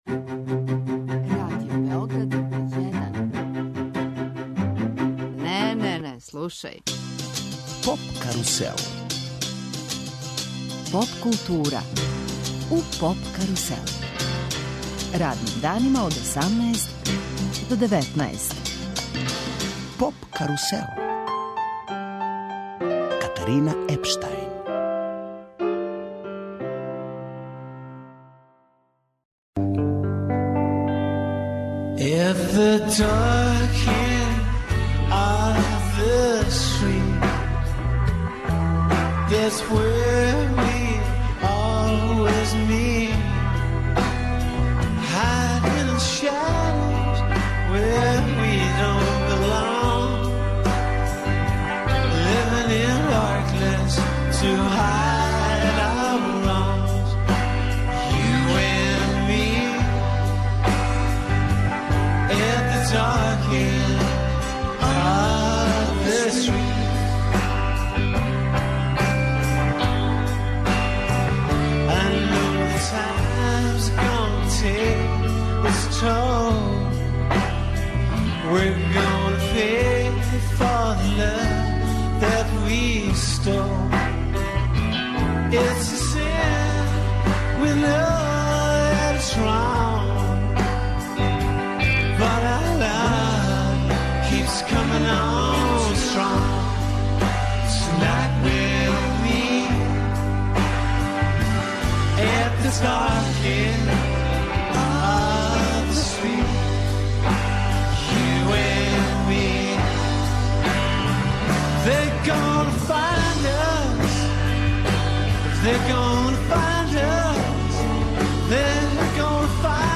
Гост емисије је Зијах Соколовић, глумац, писац и режисер.